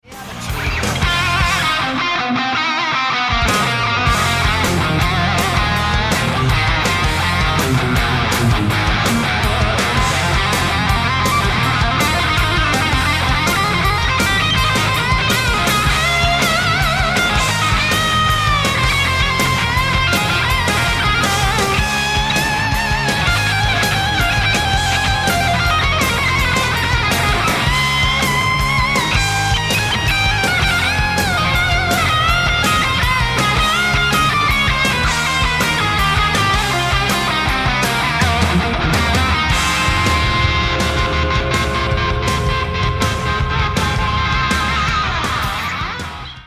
Slash Tones for ToneX, Quad Cortex & Kemper are available to ORDER NOW!! Captured from real gear and dialled in to get that tone.